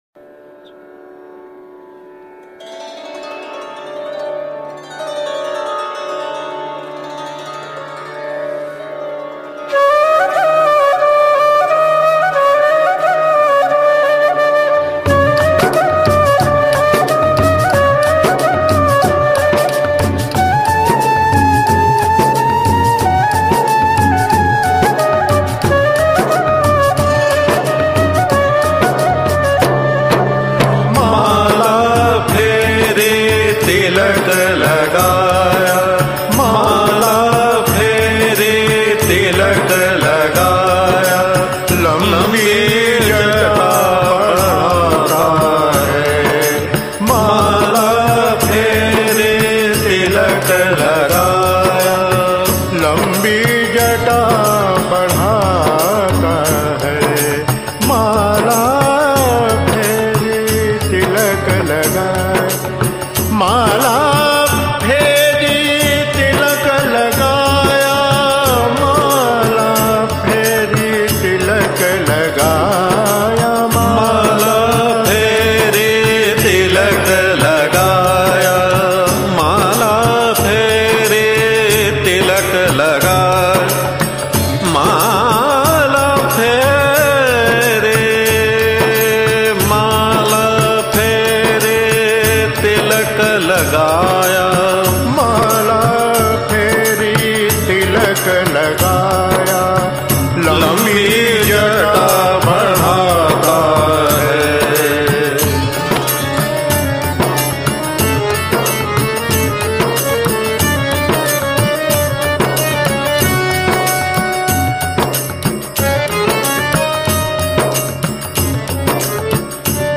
लोकगीत रूपान्तर